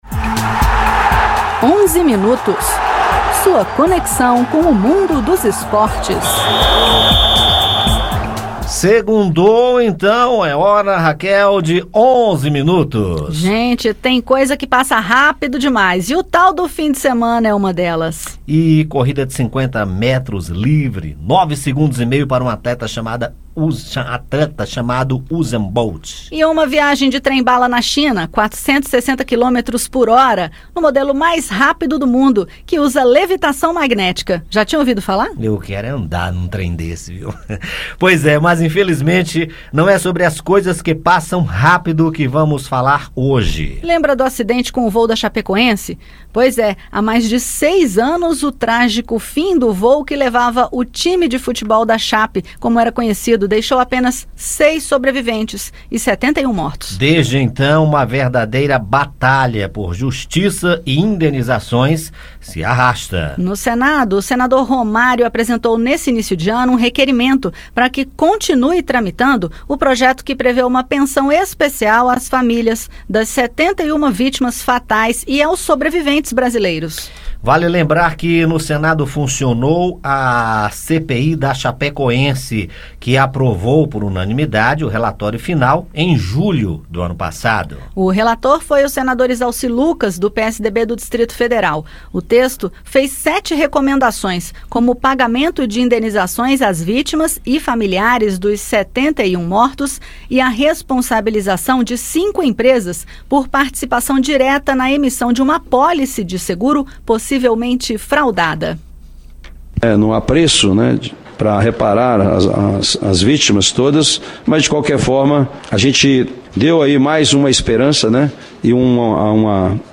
Na sequência, ouça os comentários da semana sobre esporte e os destaques do futebol.